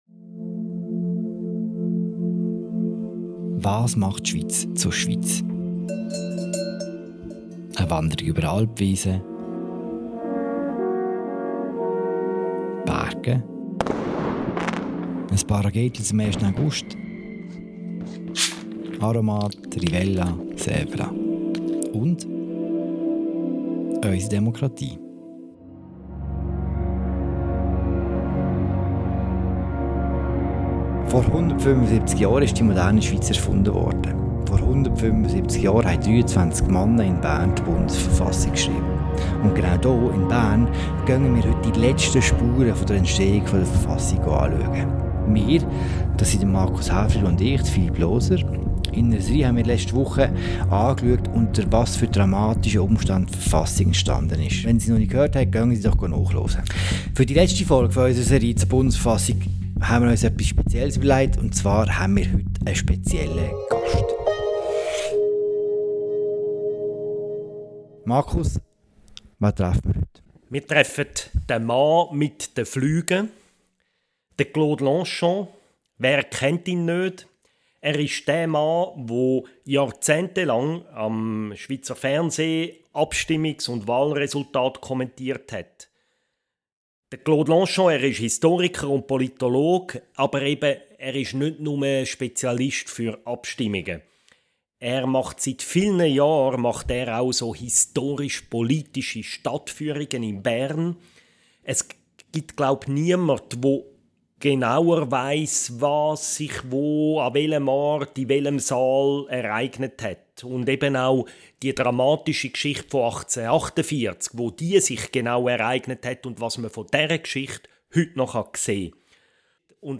Zum Abschluss der Verfassungs-Serie von «Apropos» zeigt Claude Longchamp auf einem Spazierungang durch Bern, wo man heute noch Spuren der dramatischen Enstehungsgeschichte unserer Bundesverfassung sehen kann.